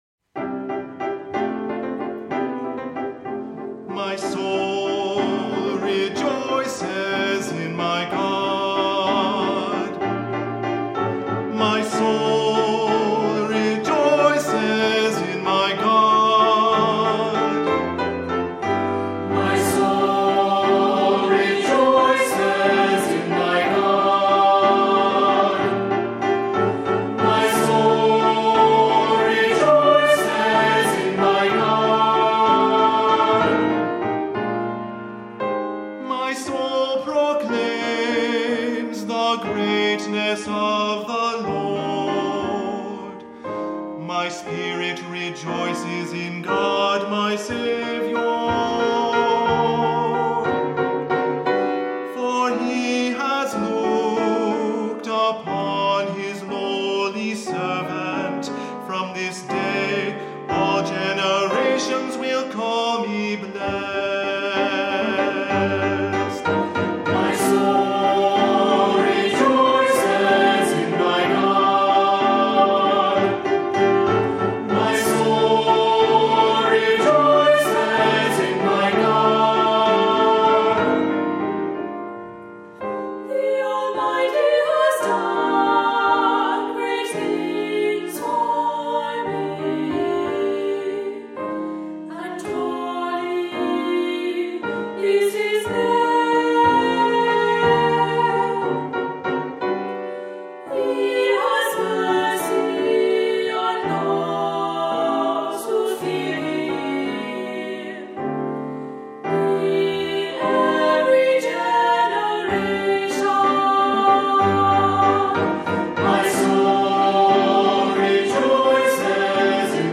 Voicing: Unison voices; Harmony; Descant; Assembly